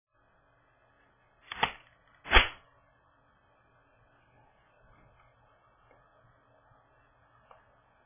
I also don't get a ka-chunk at all. The sound is fairly smooth.
For your listening pleasure, I've attached an audio recording of me chambering a round using slingshot with a brief pause between the pull and release.
1911 slow slingshot.WMA